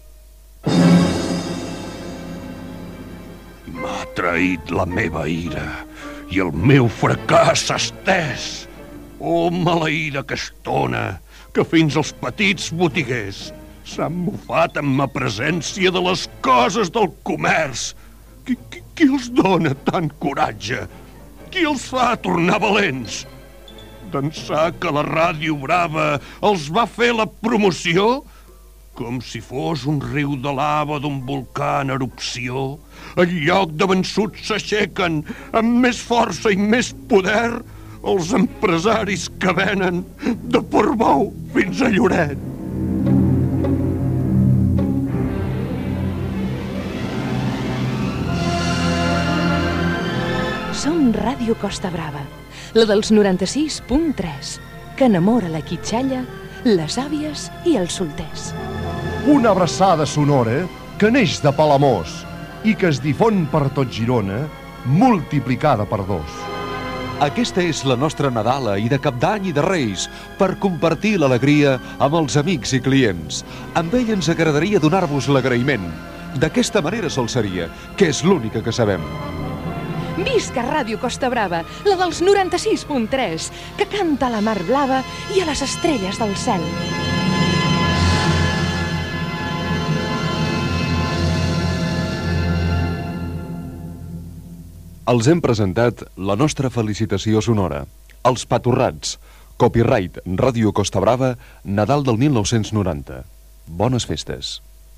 Felicitació de Nadal de l'emissora